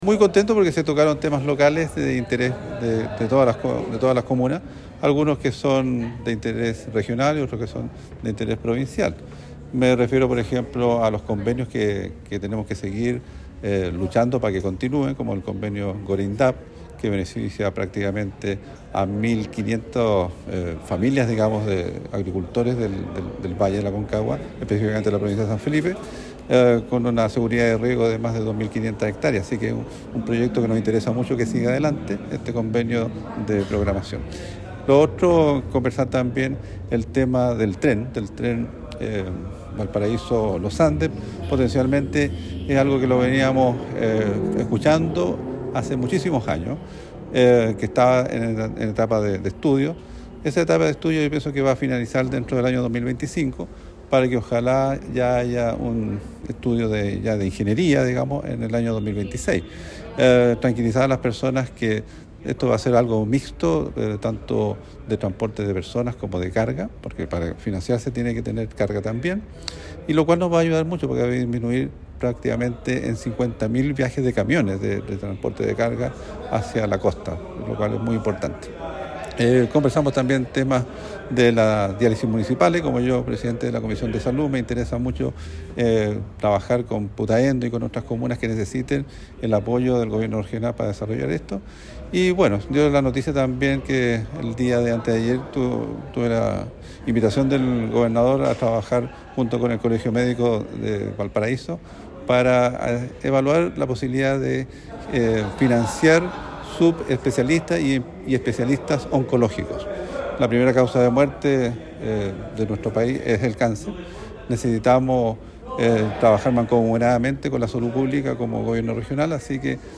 El Consejero Rodolfo Silva (RN), entregó otros detalles del encuentro.